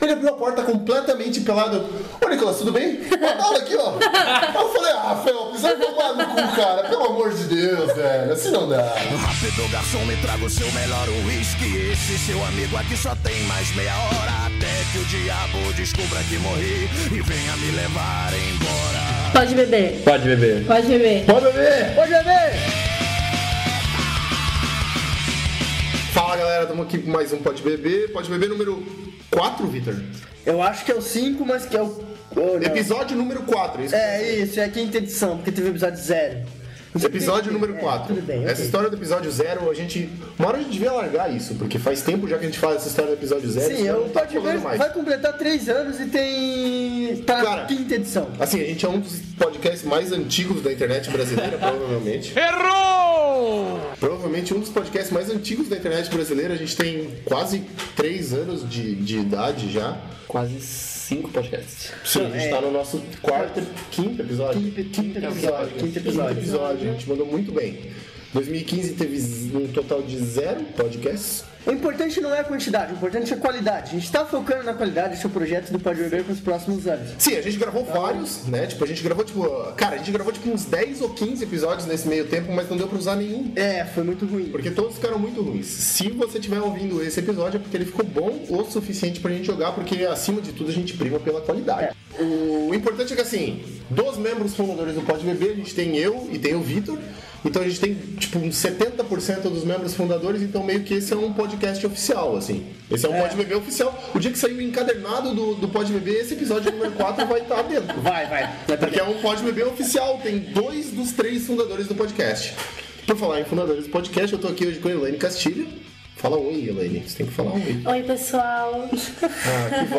Play Rate Listened List Bookmark Get this podcast via API From The Podcast Um podcast alcoólico, gravado em meio aquoso, e que não deve ser levado a sério!!